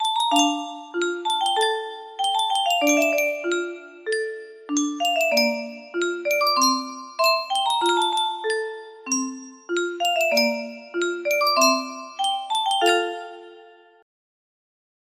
Yunsheng Music Box - Unknown Tune 1151 music box melody
Full range 60